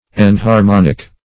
Enharmonic \En`har*mon"ic\ ([e^]n`h[.a]r*m[o^]n"[i^]k),
enharmonic.mp3